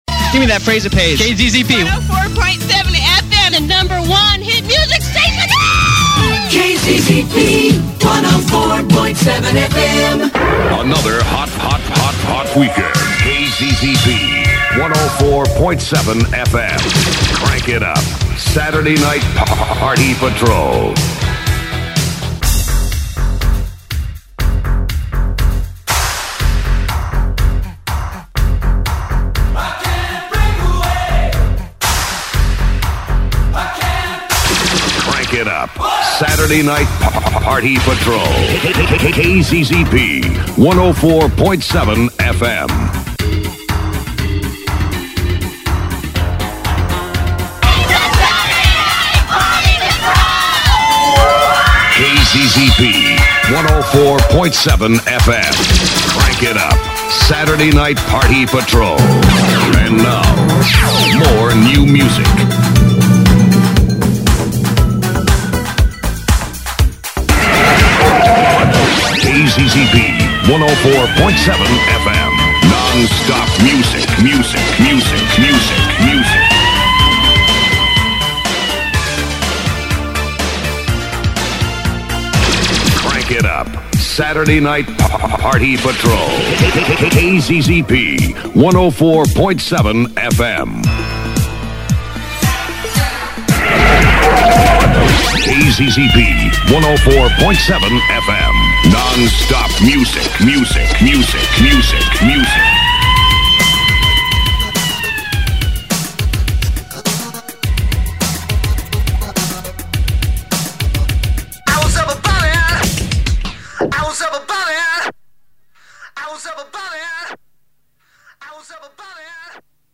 Composite of the very entertaining and creative “Saturday Night Party Patrol” on KZZP 104.7 FM Phoenix.